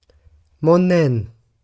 glaswegian_audio
glaswegian
scottish